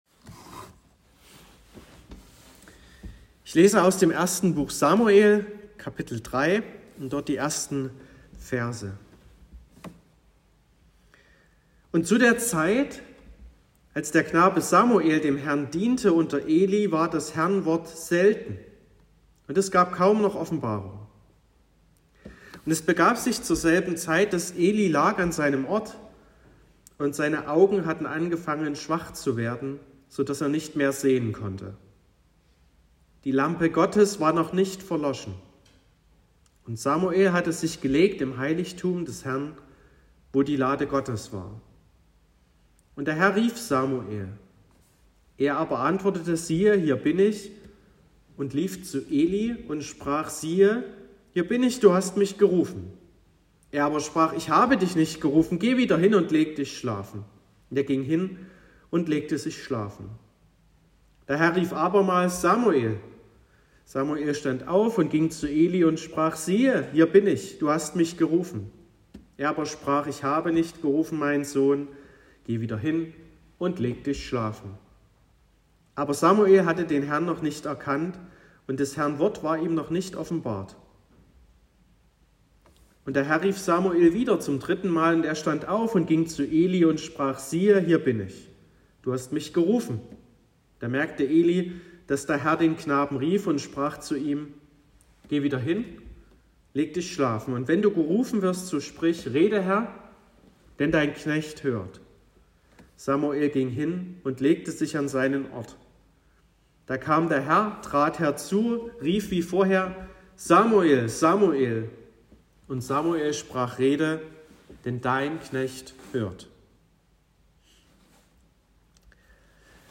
21.05.2023 – Gottesdienst
Predigt und Aufzeichnungen